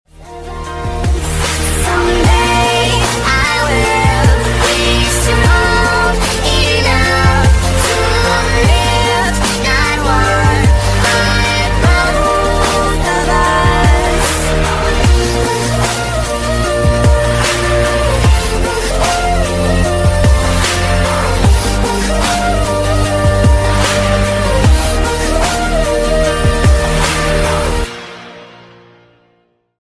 Sped up!